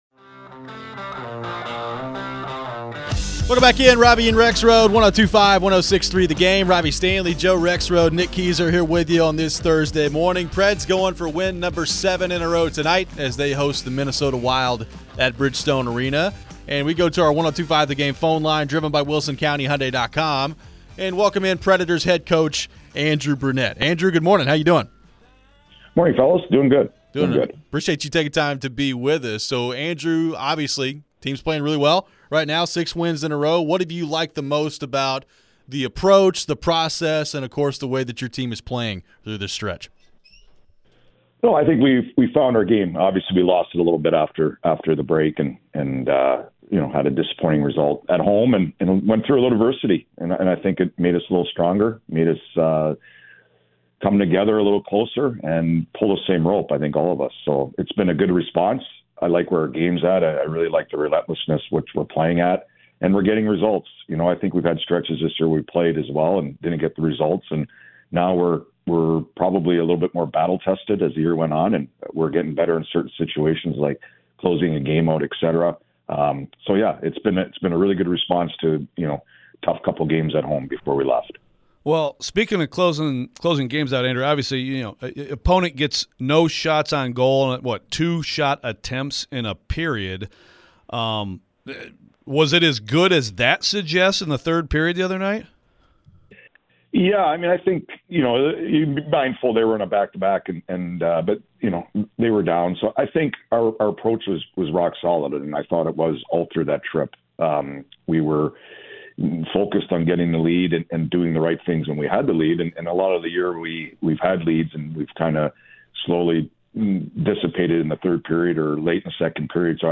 Andrew Brunette Interview (2-29-24)
Nashville Predators head coach Andrew Brunette joined the show ahead of taking on the Minnesota Wild.